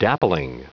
Prononciation du mot dappling en anglais (fichier audio)
Prononciation du mot : dappling
dappling.wav